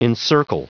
Prononciation du mot encircle en anglais (fichier audio)